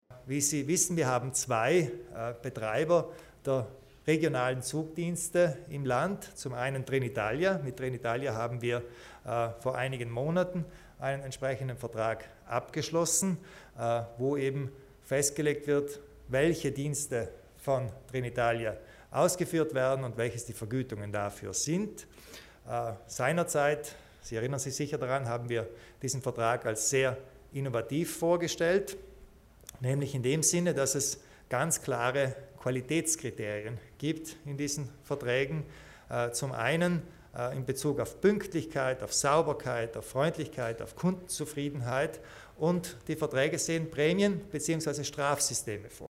Landeshauptmann Kompatscher erläutert den neuen Dienstleistungsvertrag zur Vergabe des Bahndiensts